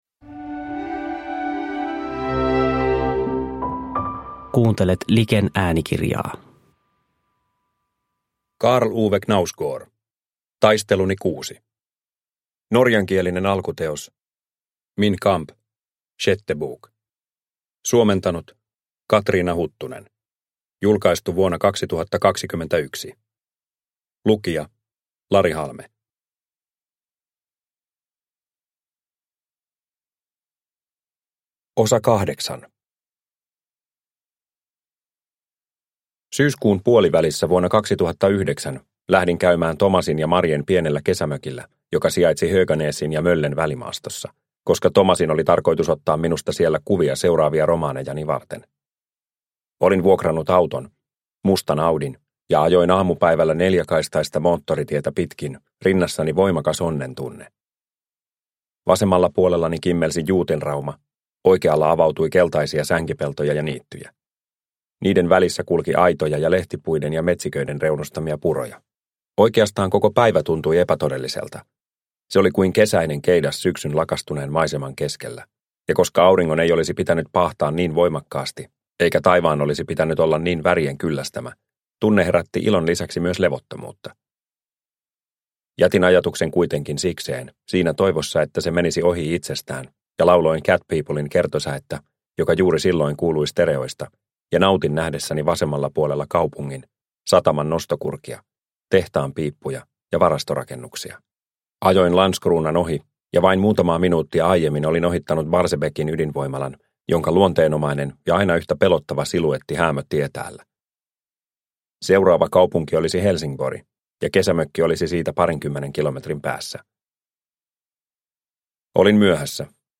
Taisteluni VI – Ljudbok – Laddas ner